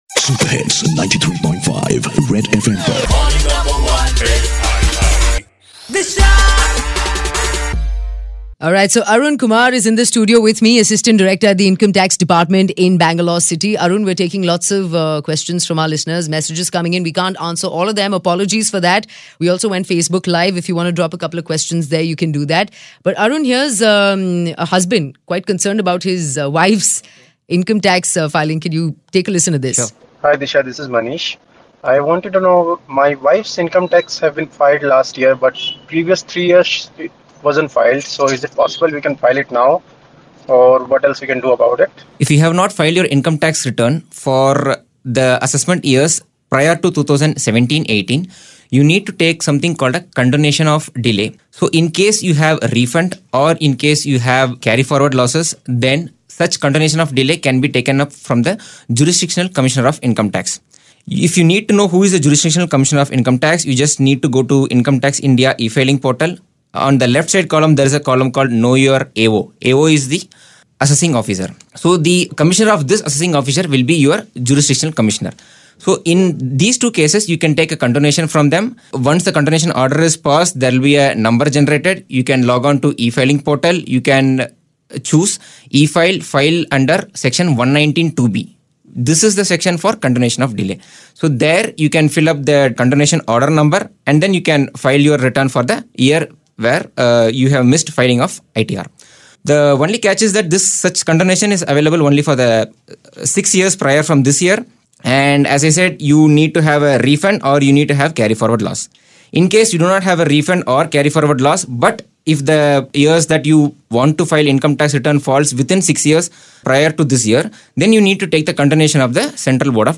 live in the studio